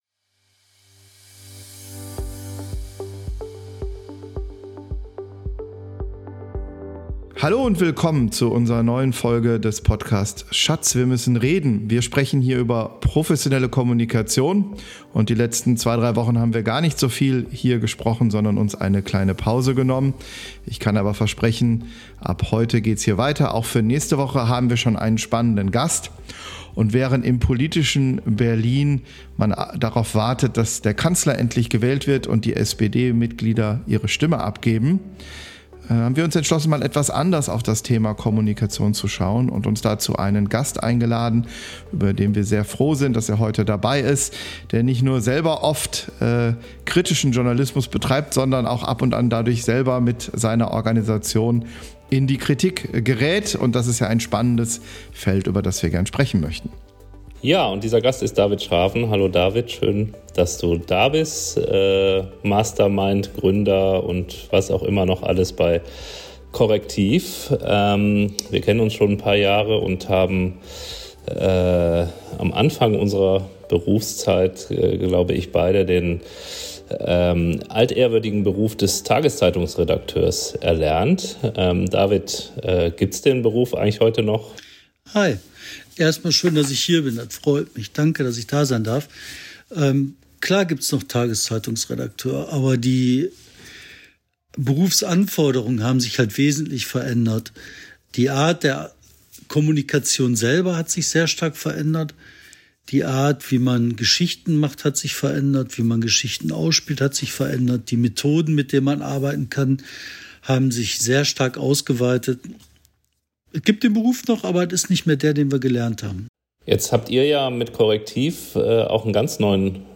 Beschreibung vor 11 Monaten Wir haben eine kurze Pause gemacht und warten, wie das politische Berlin, auf die Wahl des Bundeskanzlers, bis wir wieder mehr über politische Kommunikation sprechen - daher heute eine Folge mit einem Gast zu einem anderen Thema: Mit David Schraven dem Gründer und Publisher des gemeinnützigen Medienhauses “Correctiv” sprechen wir über seine Sicht auf Journalismus, seine Vision, wie es weitergehen kann - und wie er die Interaktion mit Rechtsanwaltskanzleien oder Unternehmenssprecherinnen und -sprechern in den Recherchen erlebt.